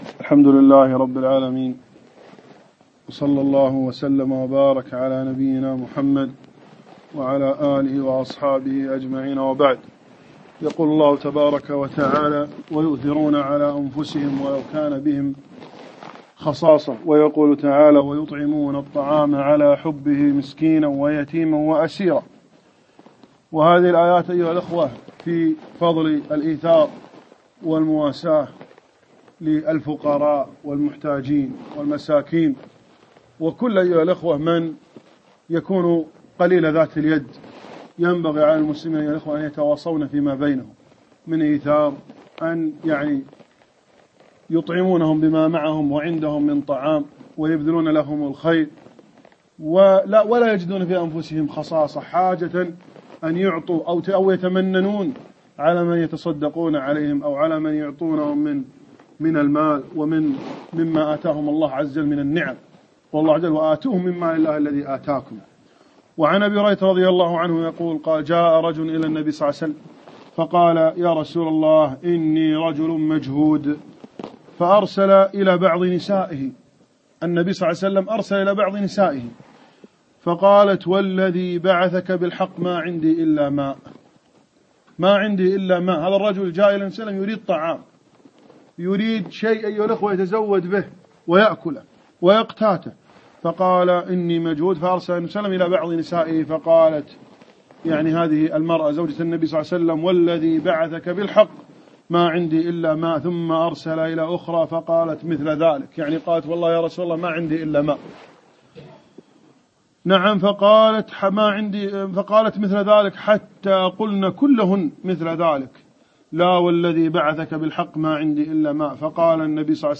نموذجا من الإيثار - كلمة